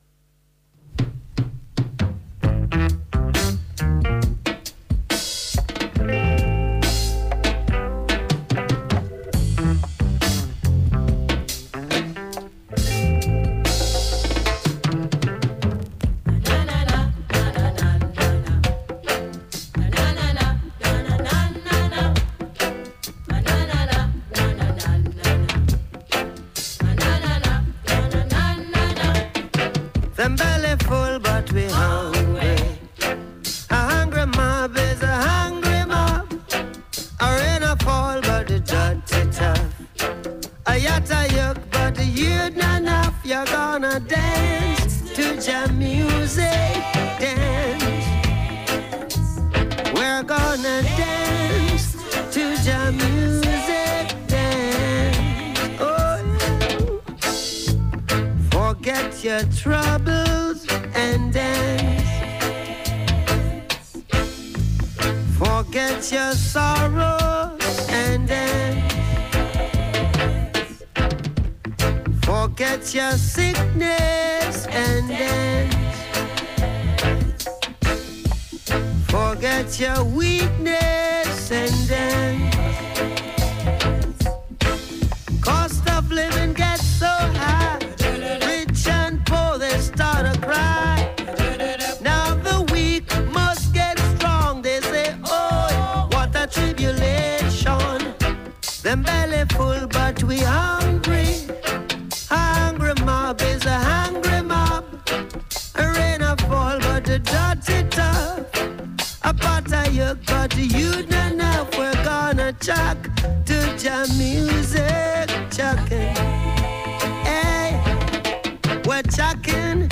in the studio last week